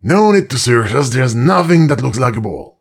brickmove06.ogg